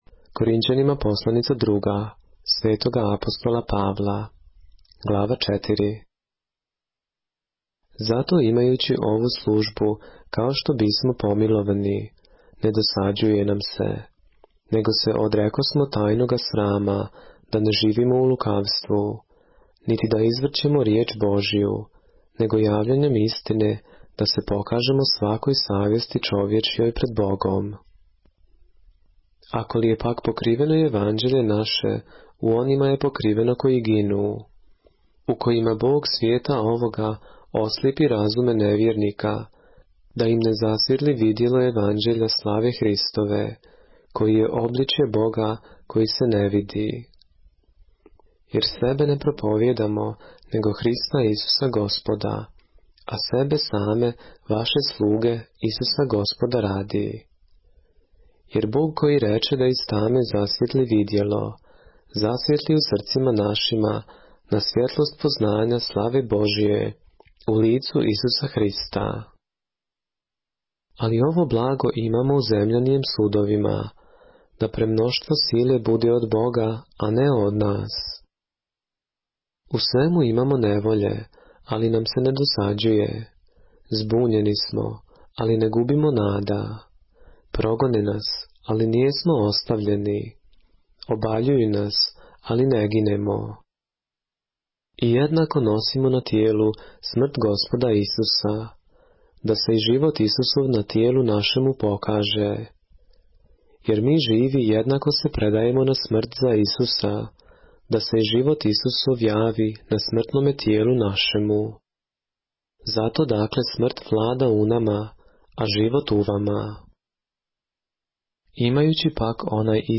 поглавље српске Библије - са аудио нарације - 2 Corinthians, chapter 4 of the Holy Bible in the Serbian language